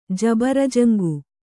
♪ jabarajaŋgu